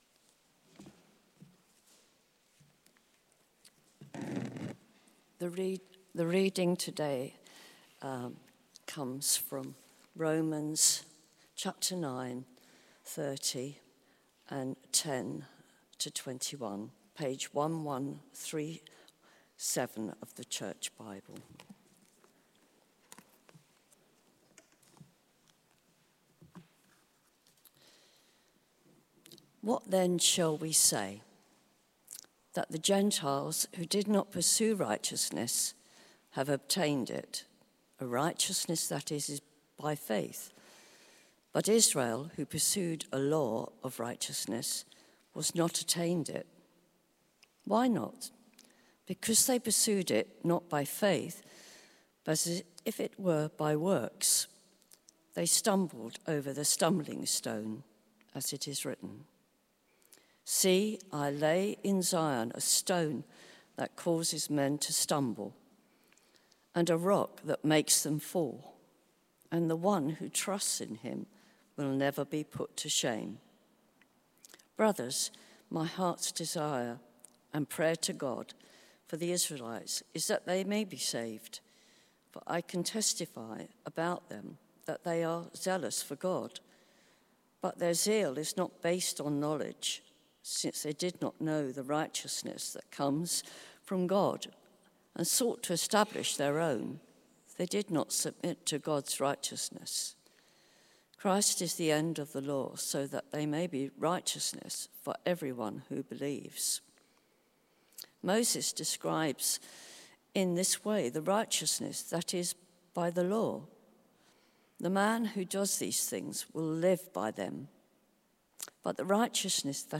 Theme: It Depends on the Word of Christ Sermon